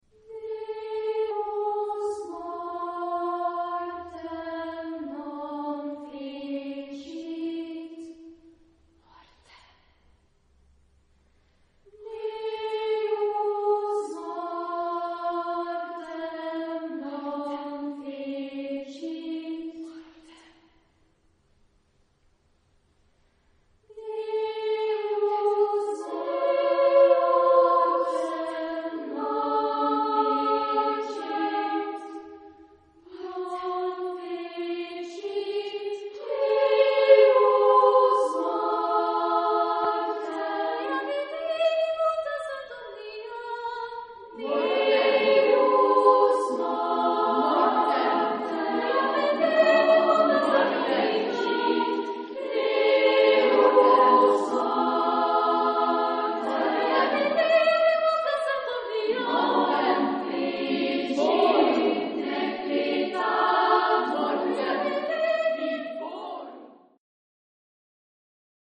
Genre-Style-Form: Sacred ; Prayer
Type of Choir: SSAA + spoken choir  (5 women voices )
Tonality: various